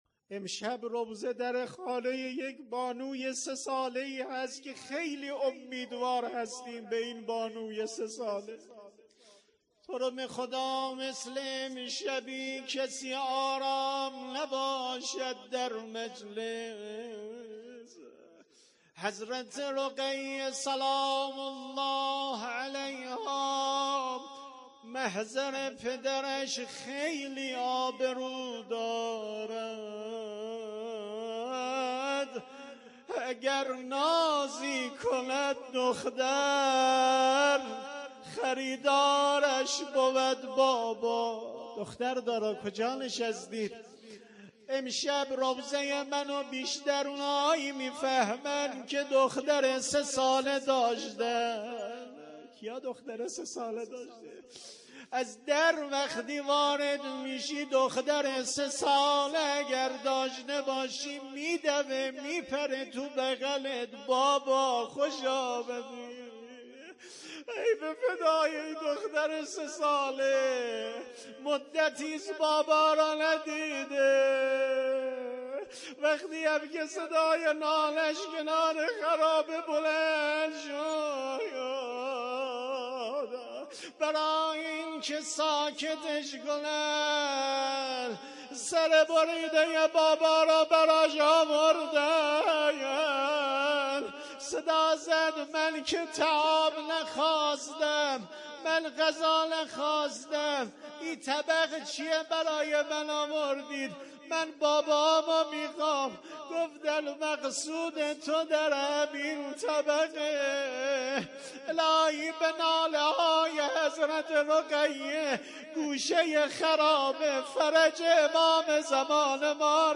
هیئت عقیله بنی هاشم سبزوار
محرم ۱۴۰۱